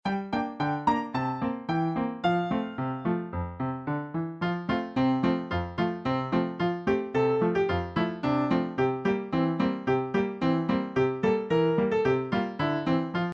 Folk Song Lyrics and Sound Clip
This song is available as sheet music and an instrumental.